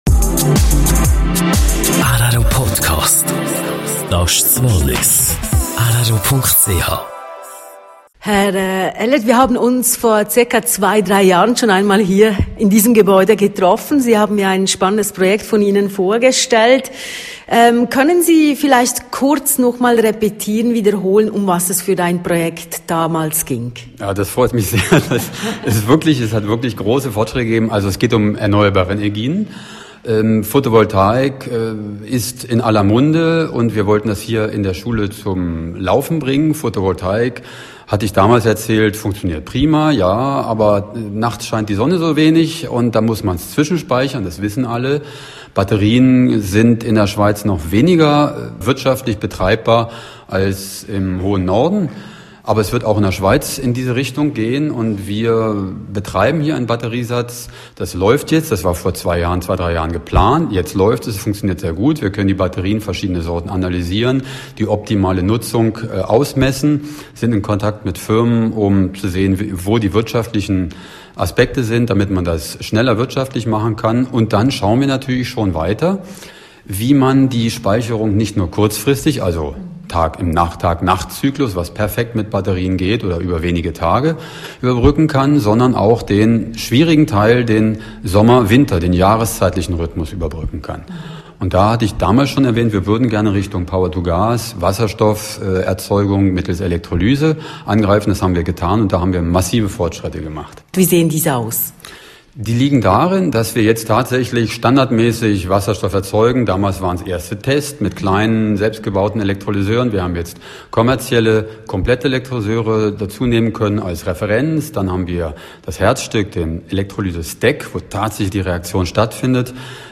Er hat während seiner Diplomarbeit einen selbst entworfenen, gefertigten und getesteten Elektrolyseur konstruiert./ik Institut Systemtechnik an der Hes-so.